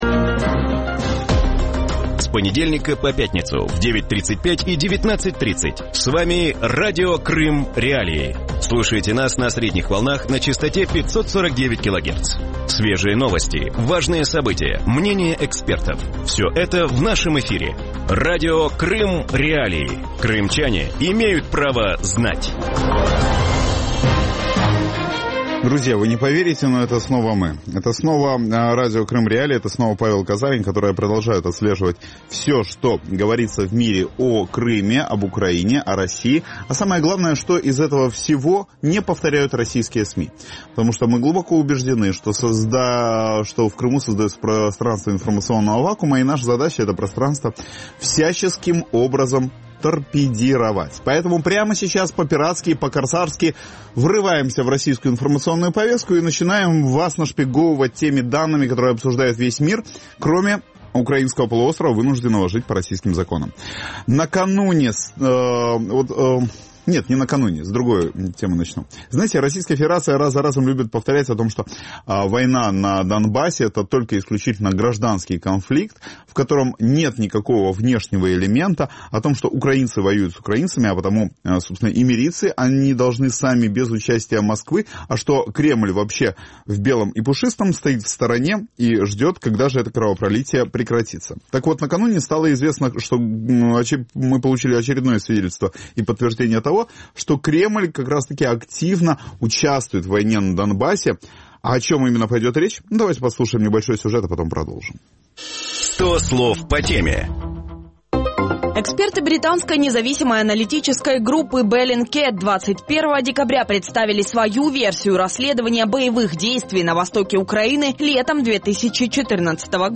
В вечернем эфире Радио Крым.Реалии говорят о новом отчете международной исследовательской группы Bellingcat, в котором идет речь об артобстрелах Украины с российской территории. Что нового рассказали военные эксперты в своем докладе о российской агрессии и может ли он повлиять на политическую ситуацию? На эти вопросы ответят украинские военные эксперты